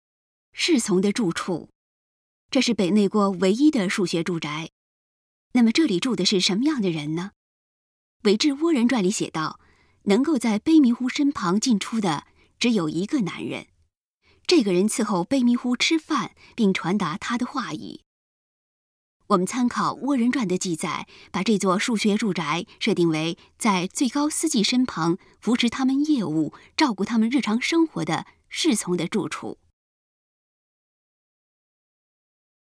语音导览 前一页 下一页 返回手机导游首页 (C)YOSHINOGARI HISTORICAL PARK